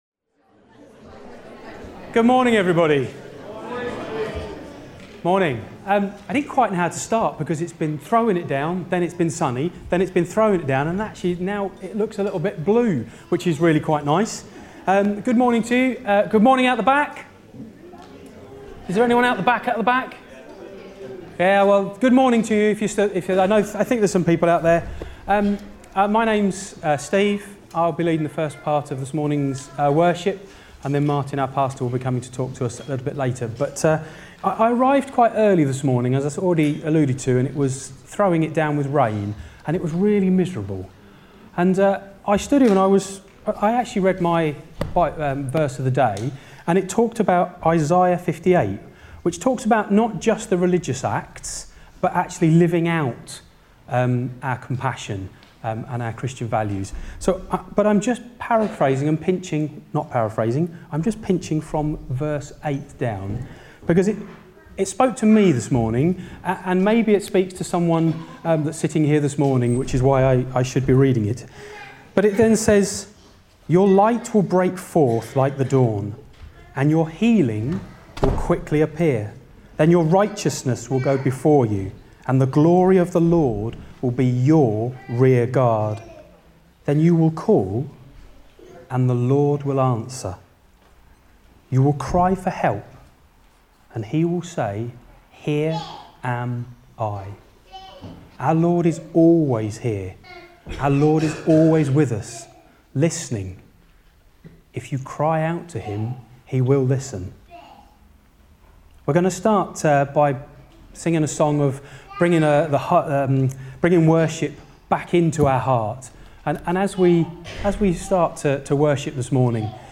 23 November 2025 – Morning Service